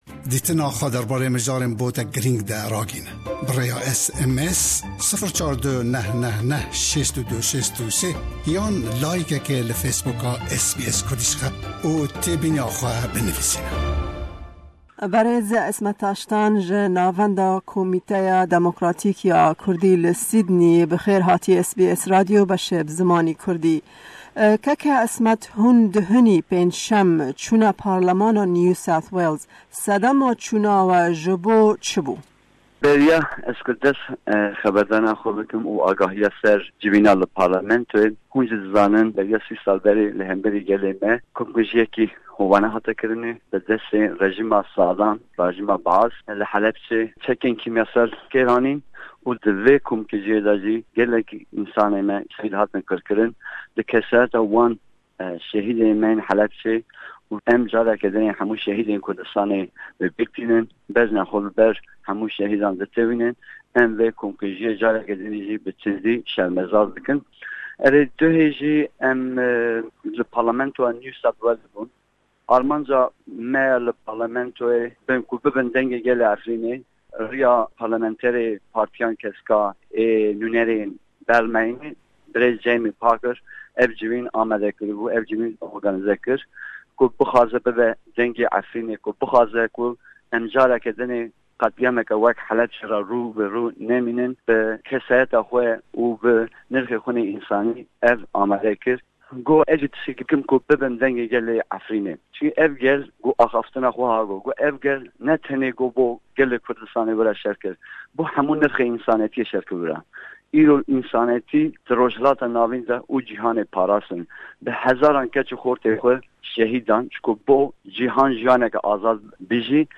Me derbarê encamên civînê hevpeyvînek